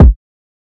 Kick (YouGotMe2).wav